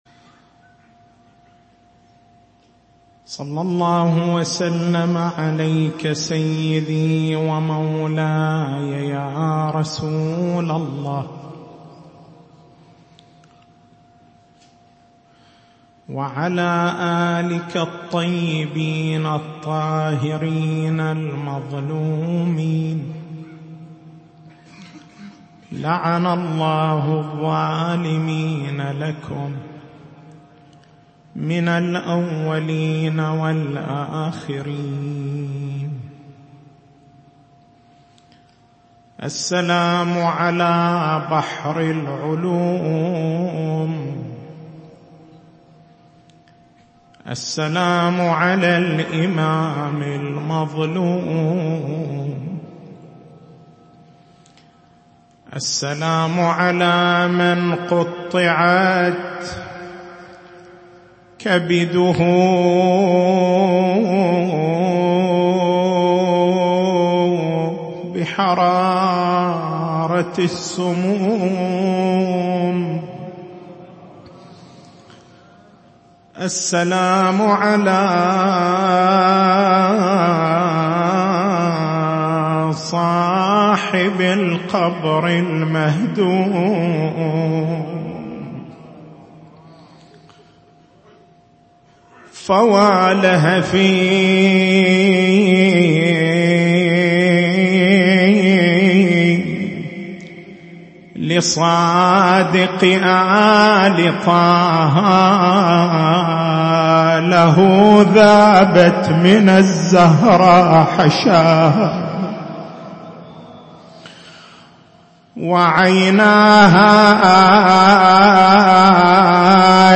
تاريخ المحاضرة: 25/10/1439 محور البحث: هل تدلّ حيرة أجلّاء أصحاب الإمام الصادق (ع) على أنّ عقيدة التنصيص على الأئمة الاثني عشر بأسمائهم لم تكن واضحة عند عموم الشيعة، وإنما هي عقيدة استحدثها علماء الشيعة في زمن الغيبة؟